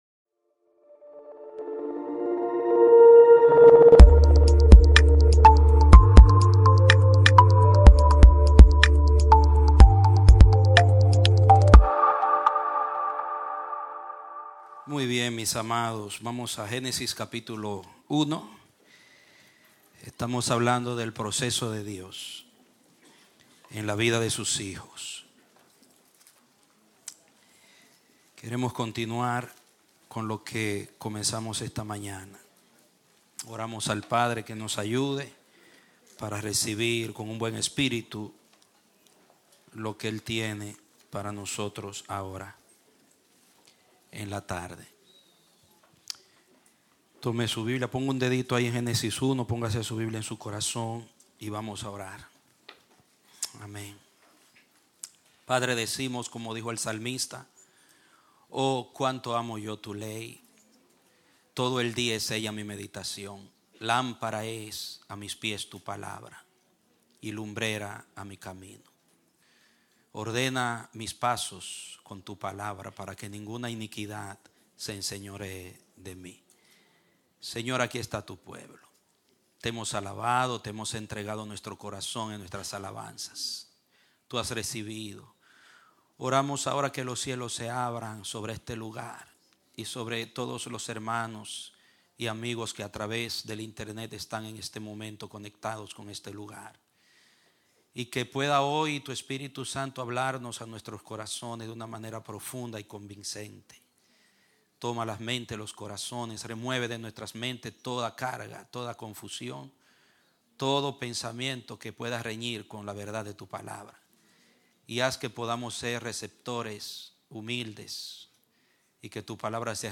Un mensaje de la serie "Procesos ."